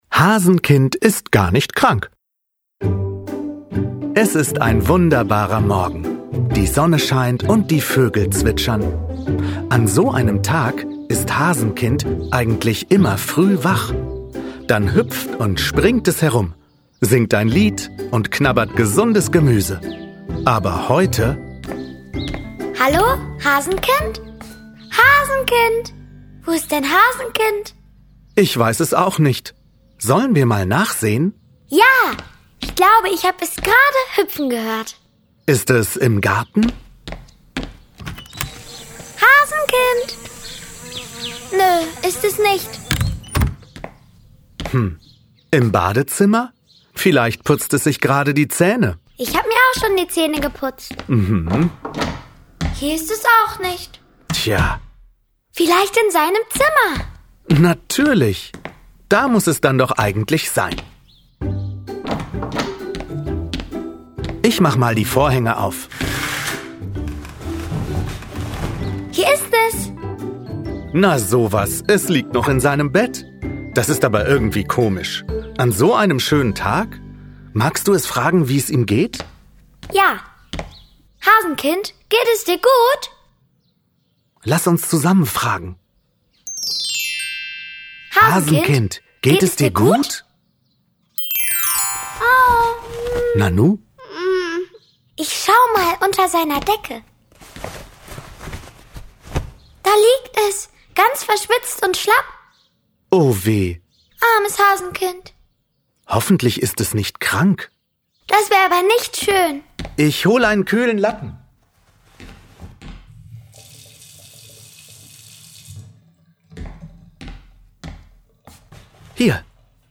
Erweiterte Neuausgabe. Hörspiele (1 CD)
In einfacher Sprache führen fünf kleine Alltagsabenteuer durch einen Tag mit Hasenkind. Die fröhlichen Hörspiele laden Kindern ab zwei Jahren zum Zuhören und Mitmachen ein - mit vielen lustigen Geräuschen und einem Titelsong mit Ohrwurmgarantie!Jetzt mit zwei Bonus-Geschichten: »Hase aufgewacht« und »Hasenkind, komm essen!«